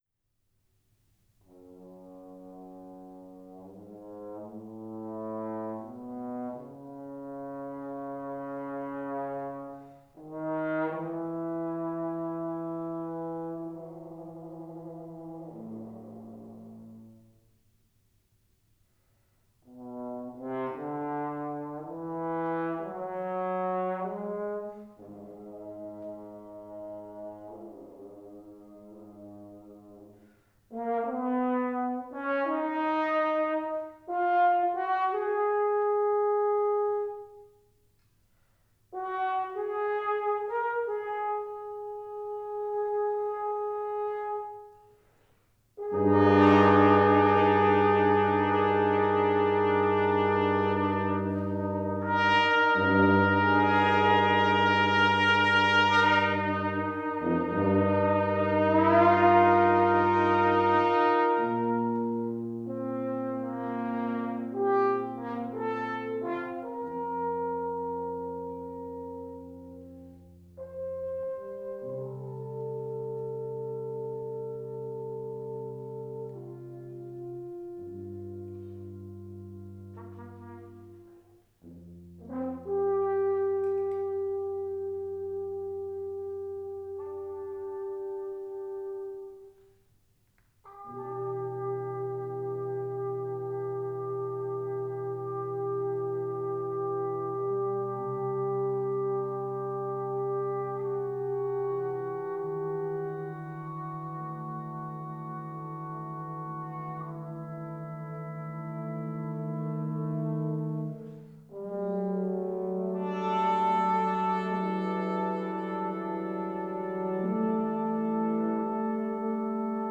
Brass quintet
trumpet
horn
trombone
tuba